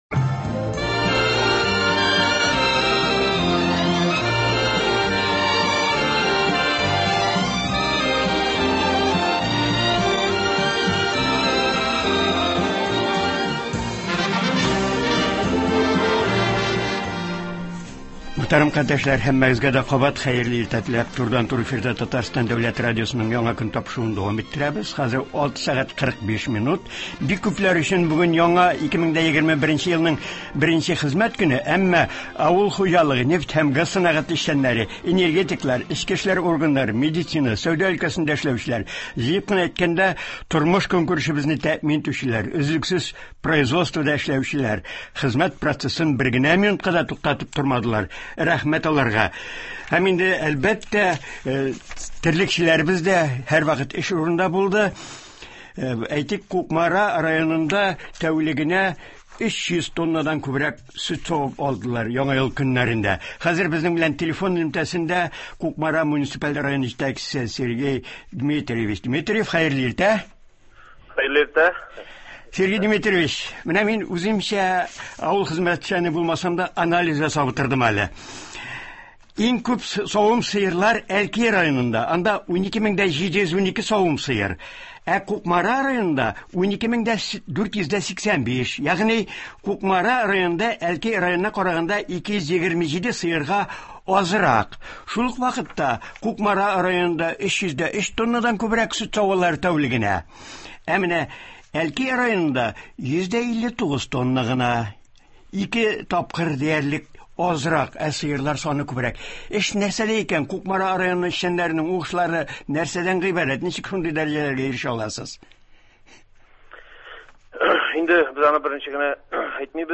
Кукмара районында, мәсәлән, көн саен 303 тоннадан күбрәк сөт саудылар. Болар хакында телефон элемтәсе аша Кукмара муниципаль районы җитәкчесе Сергей Димитриев сөйли.